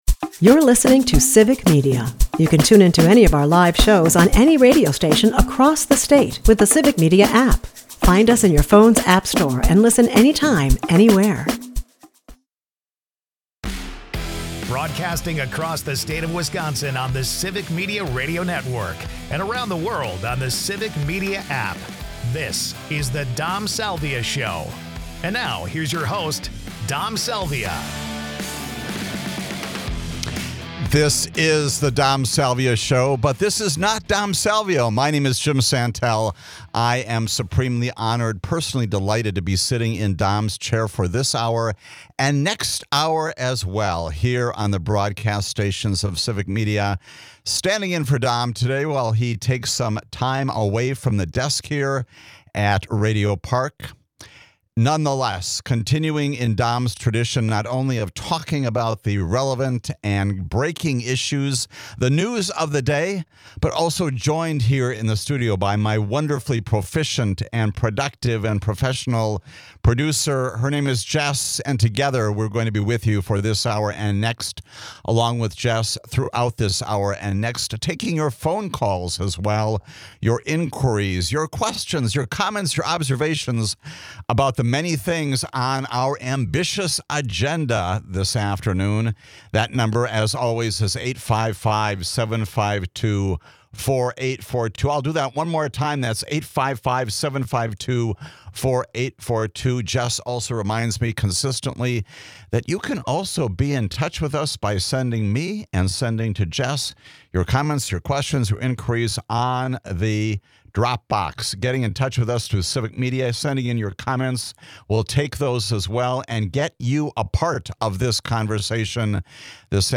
Jim Santelle guest hosts today to talk about the lifesaving and critically important work of the US Agency for International Development.